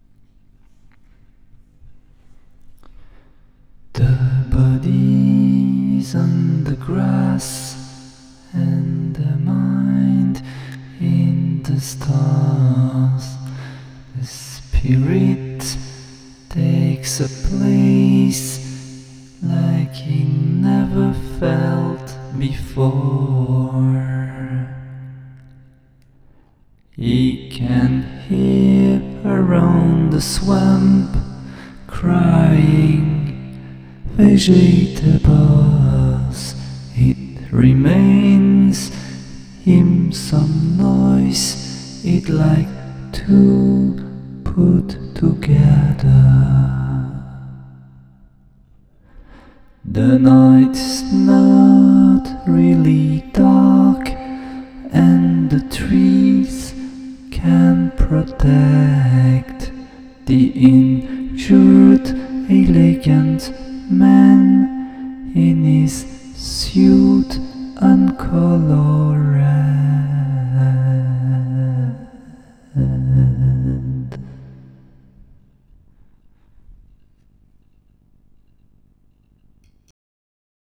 une courte chanson a capella.
La voix s’y multiplie
Prend d’autres formes.